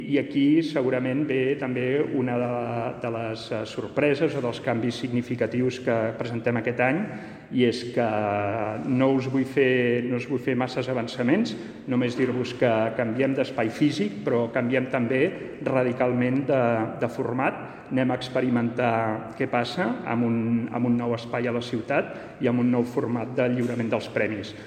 tall-de-veu-2-del-regidor-jaume-rutllant-sobre-els-premis-literaris-2021